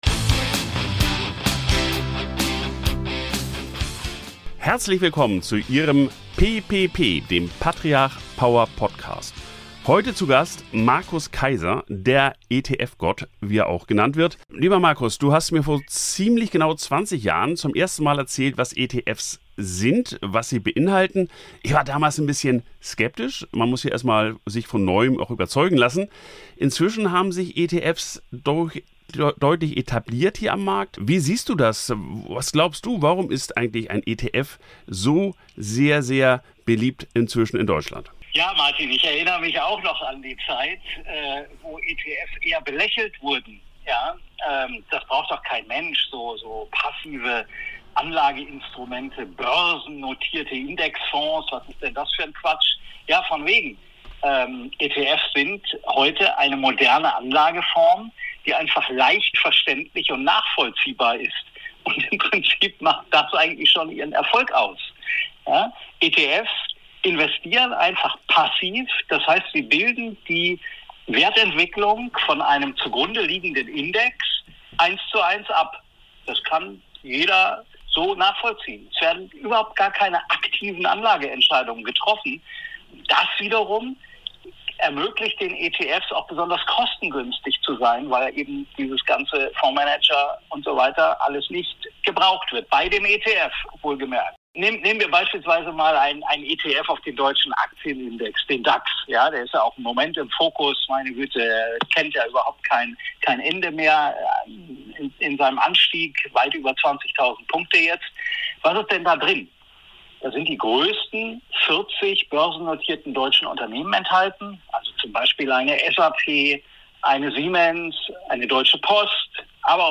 Ein fundiertes Gespräch über zeitgemäße Anlagestrategien, Marktentwicklungen und den intelligenten Einsatz von ETFs im Portfolio.